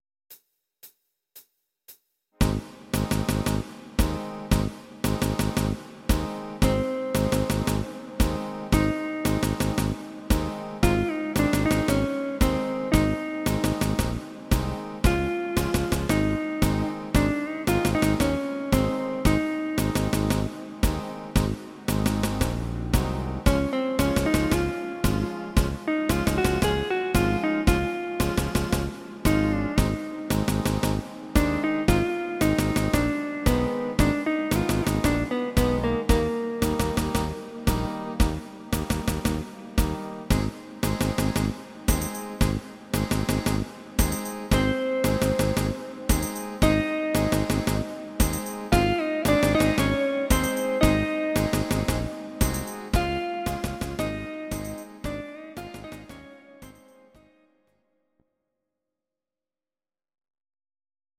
instr. Gitarre